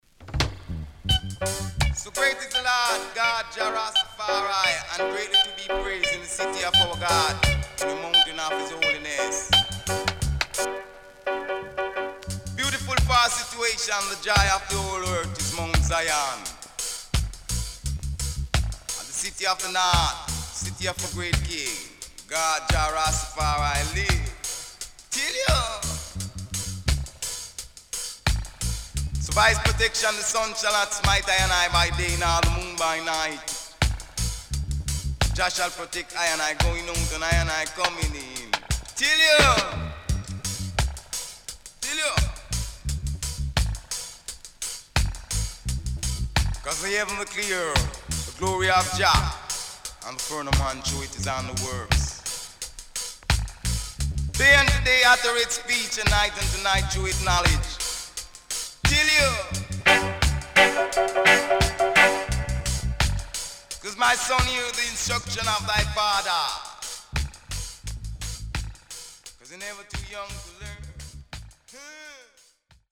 Nice Deejay.Small Hiss